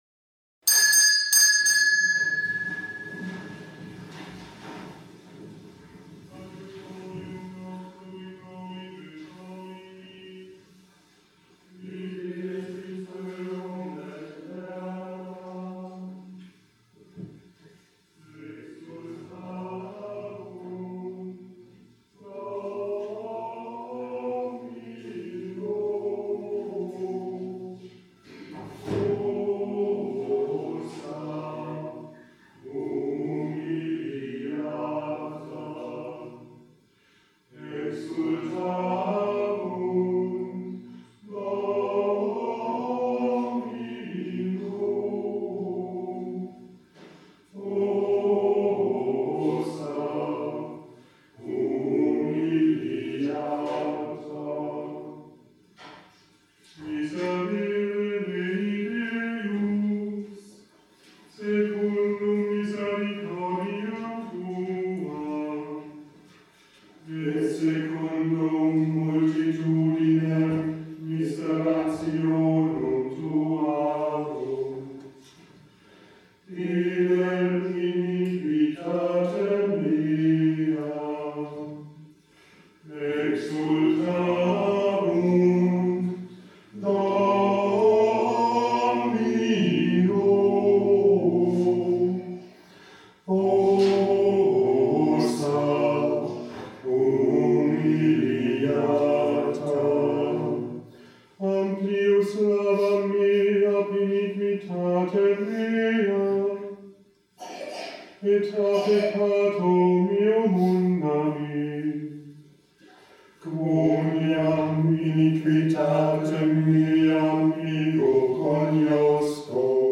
Gottesdienst am Sonntag 11. März 2017 in der Kirche St. Johann Baptist in Mooshausen
und der Schola Cantorum Lorchensis, Lorch.